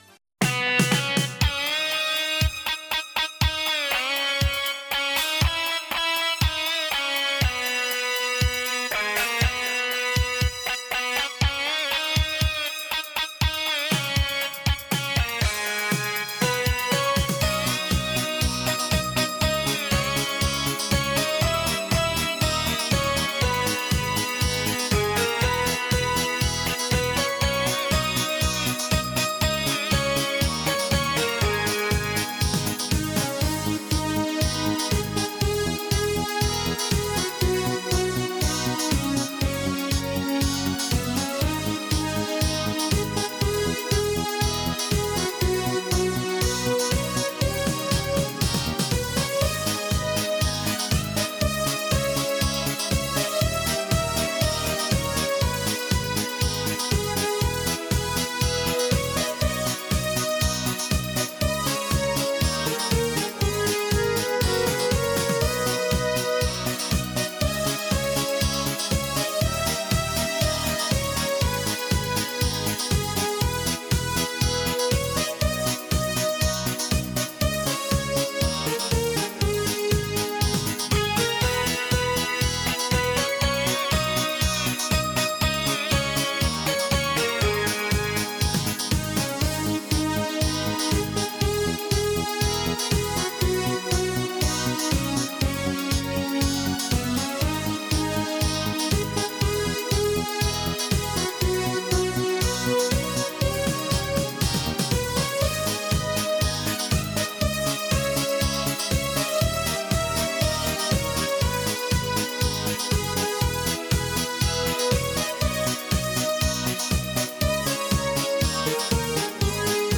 Минус татарской песни